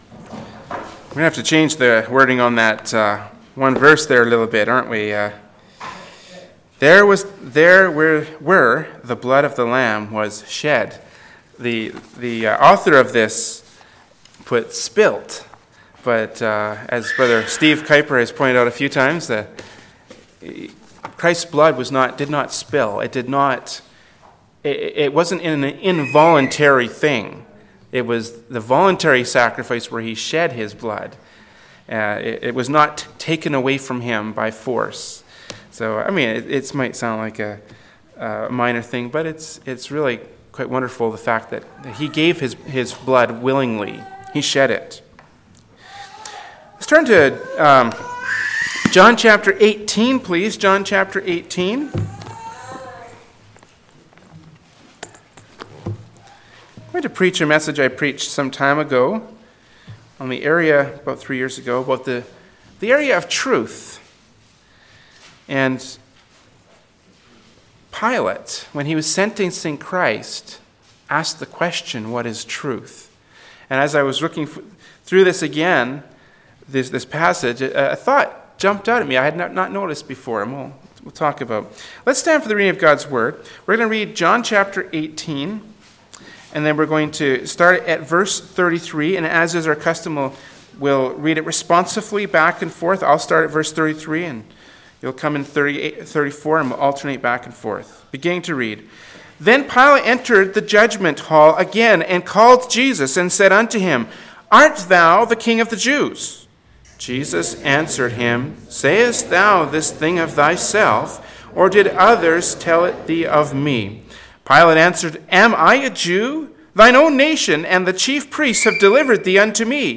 “What is Truth?” from Sunday Morning Worship Service by Berean Baptist Church.